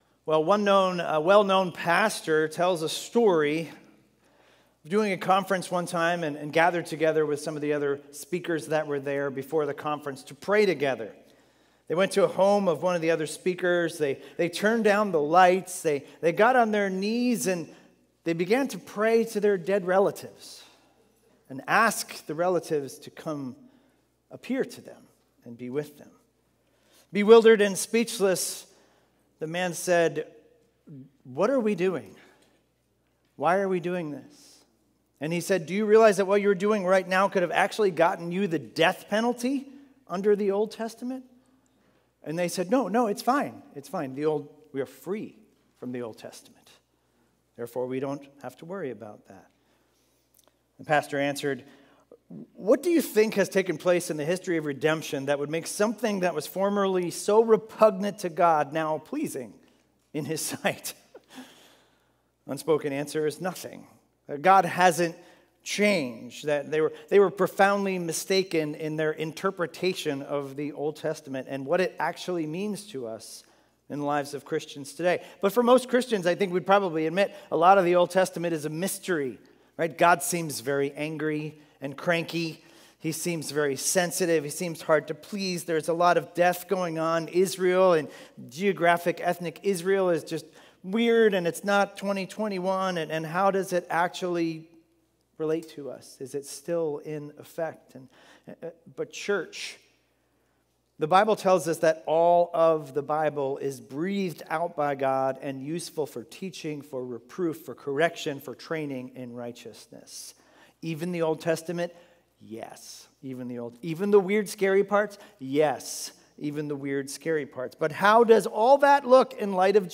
Expositional teaching series through the book of Matthew - starting Sunday, Dec 6, 2020